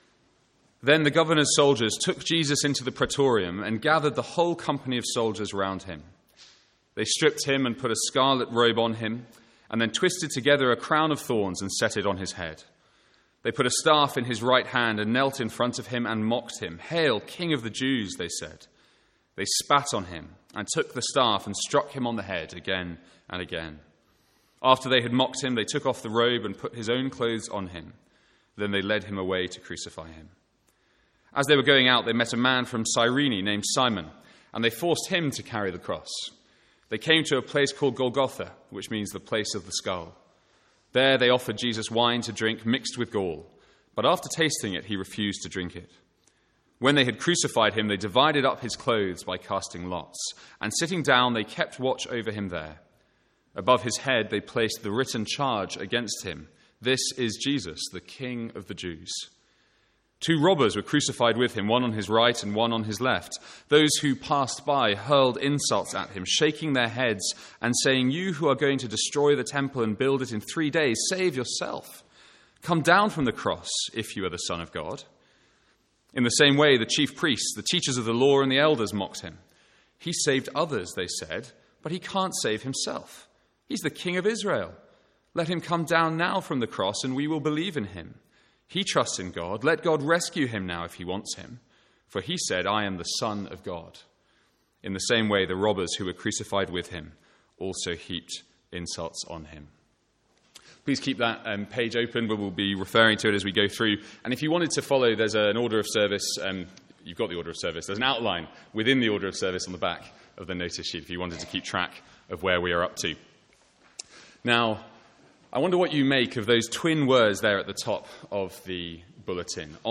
From the Sunday morning series in Matthew.
Sermon Notes